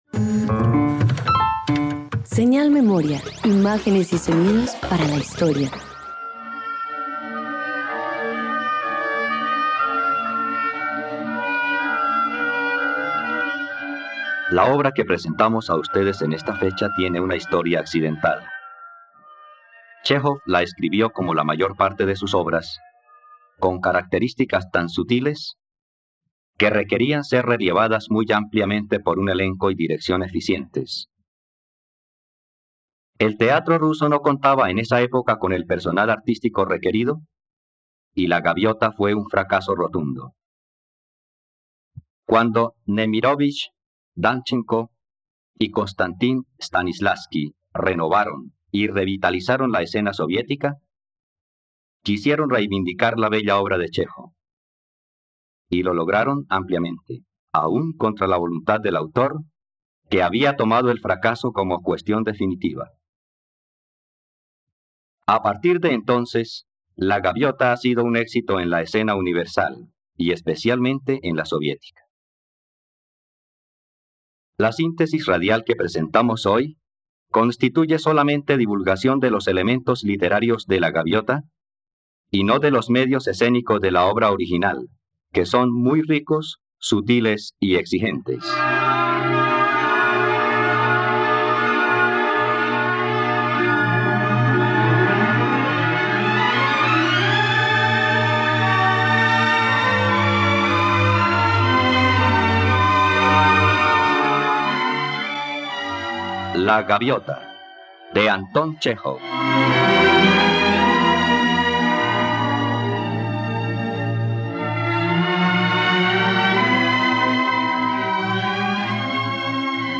..Radioteatro. Escucha la adaptación radiofónica de “La Gaviota” del dramaturgo ruso Anton Chejov, por la plataforma streaming RTVCPlay.